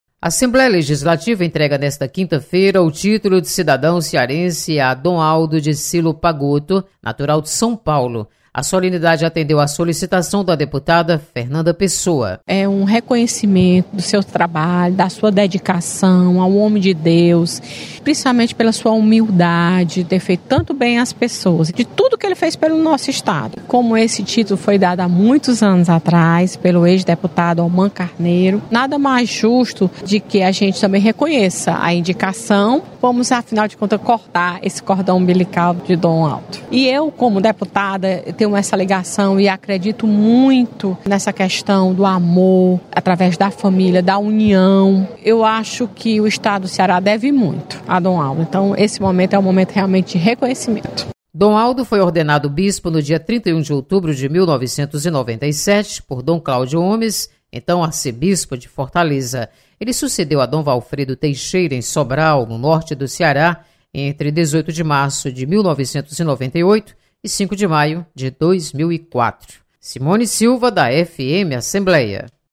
Assembleia homenageia, em sessão solene, o bispo Dom Aldo Pagotto.